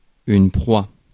Ääntäminen
France: IPA: [pʁwa]